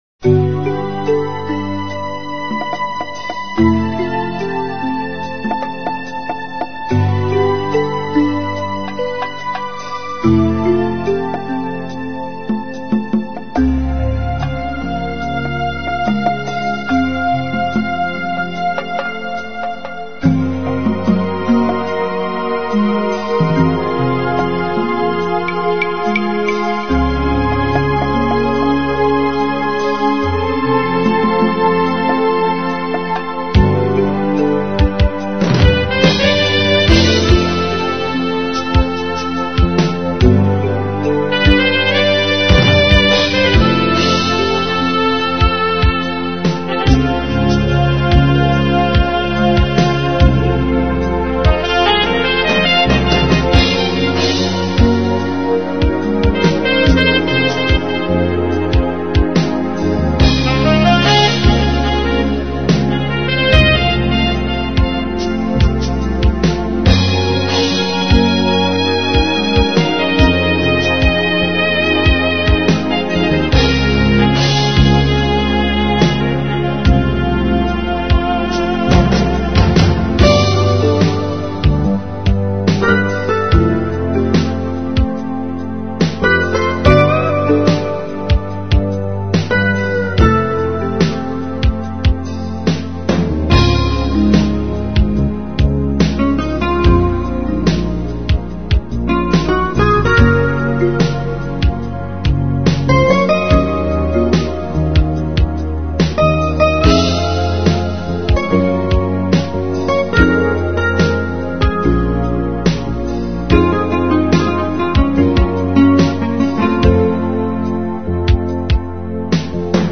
midi demo
24 Kbps 22.050 KHz mono